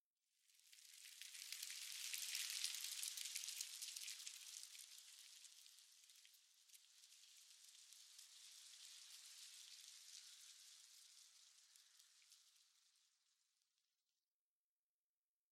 LeafRustle.mp3